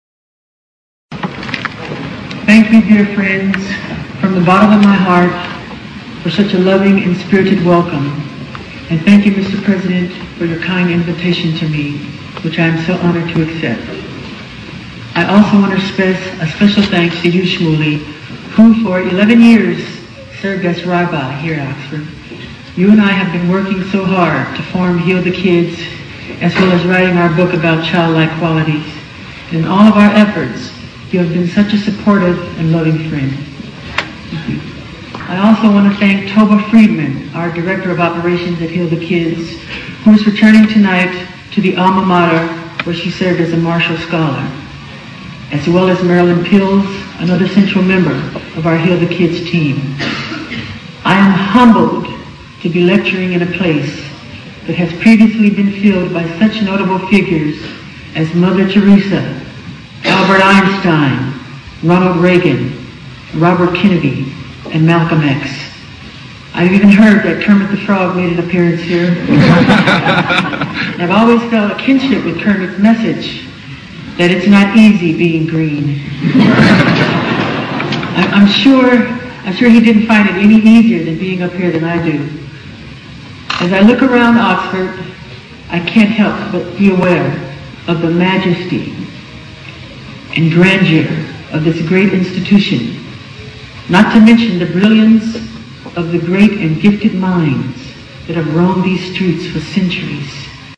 借音频听演讲，感受现场的气氛，聆听名人之声，感悟世界级人物送给大学毕业生的成功忠告。